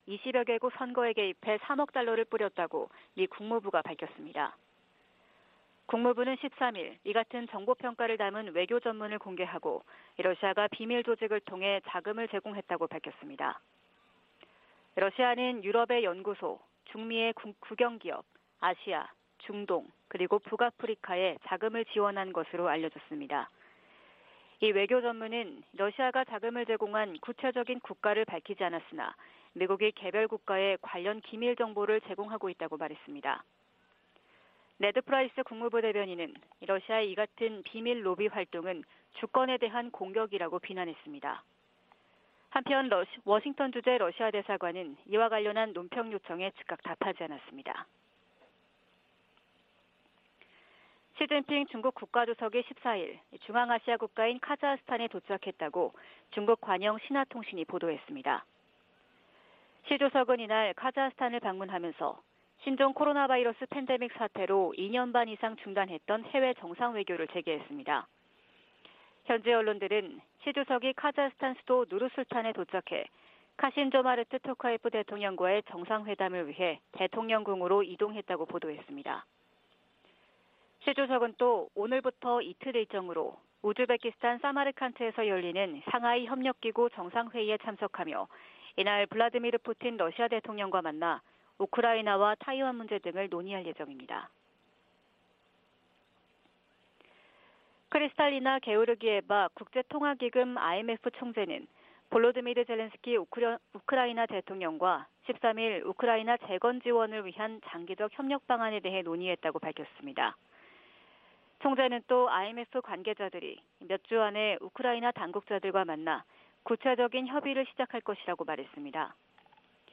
VOA 한국어 '출발 뉴스 쇼', 2022년 9월 15일 방송입니다. 북한의 핵 무력정책 법제화가 유일지배체제의 근본적 모순을 드러내고 있다고 전문가들이 분석하고 있습니다. 미 상원의원들이 북한의 핵 무력정책 법제화에 우려와 비판의 목소리를 내고 있습니다. 유엔 인권기구가 북한 지도부의 코로나 규제 조치로 강제노동 상황이 더 악화했을 수 있다고 경고했습니다.